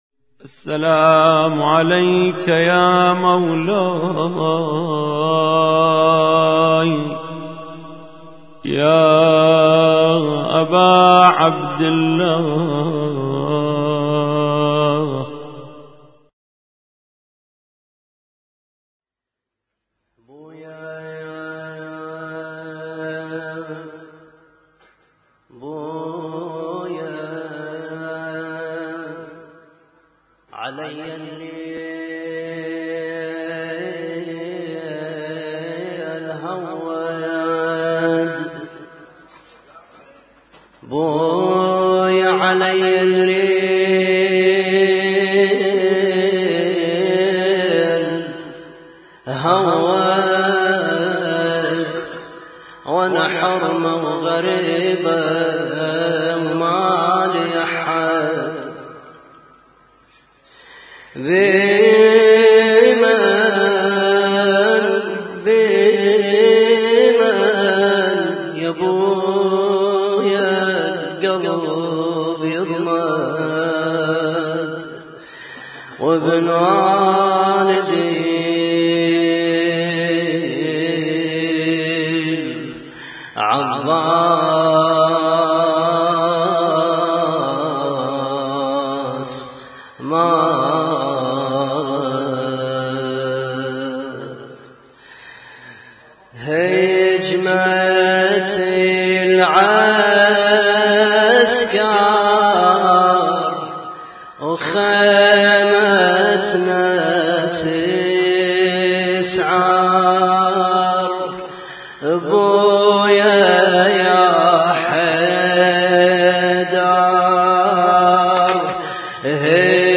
Husainyt Alnoor Rumaithiya Kuwait
لطم - ليلة 11 محرم 1436